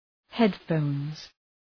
Προφορά
{‘hed,fəʋnz}